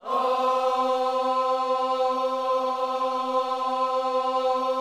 OHS C 4F.wav